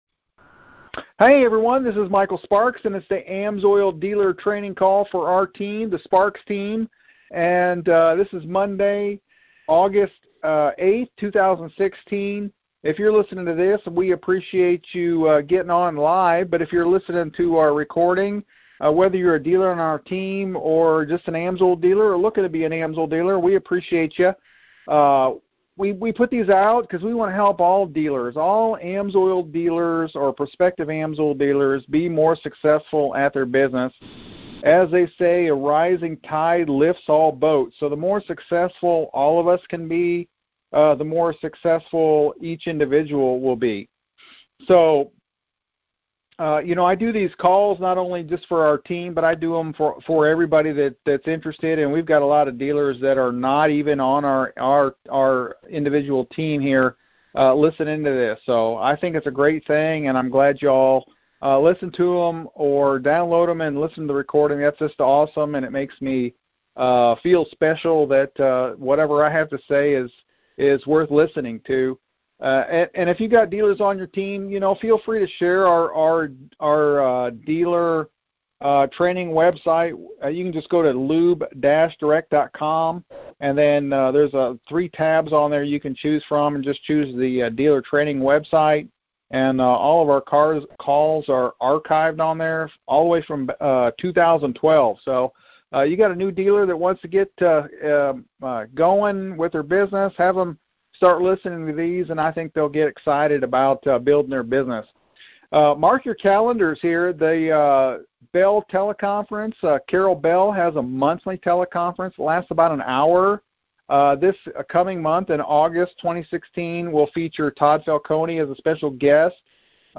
Team Training Call